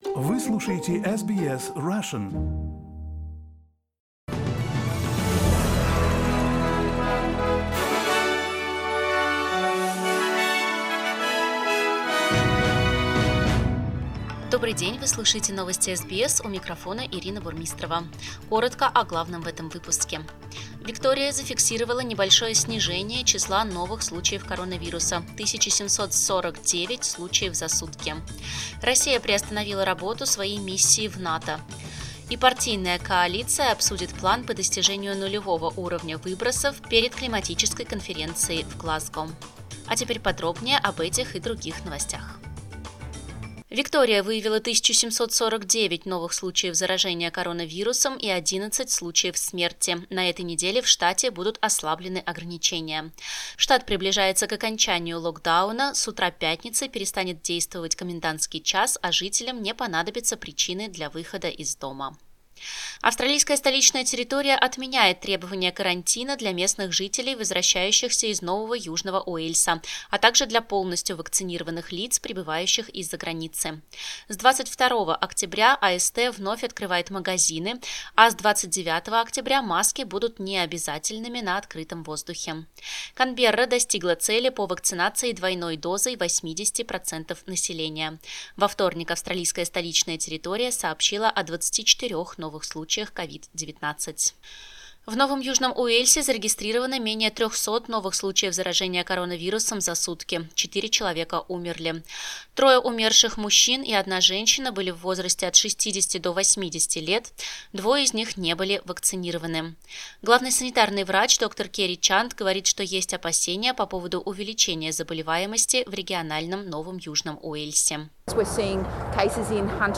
SBS news in Russian - 19.10